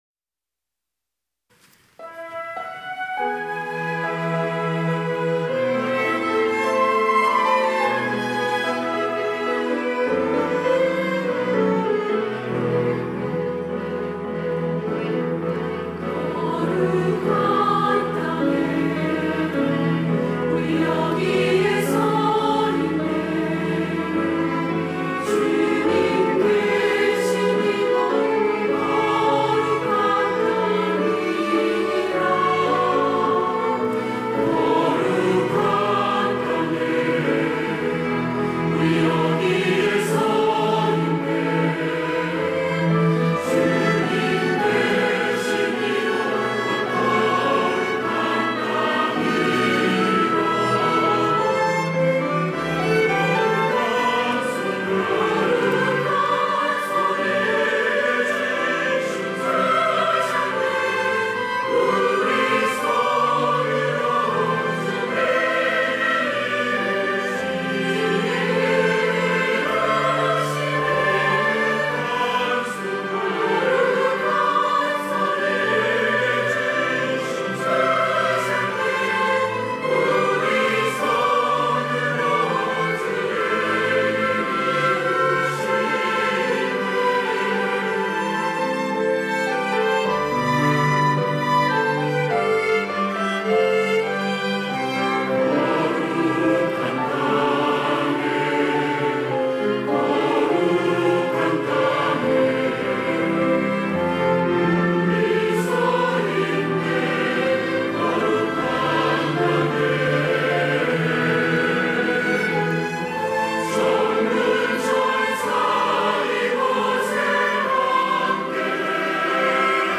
할렐루야(주일2부) - 거룩한 땅에
찬양대